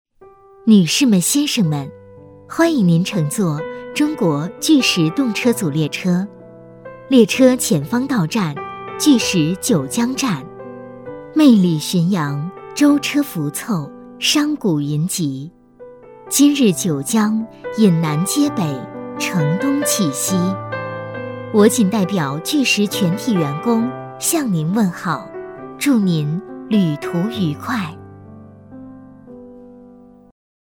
女218- 高铁报站
女218-温柔甜美 成熟厚重
女218- 高铁报站.mp3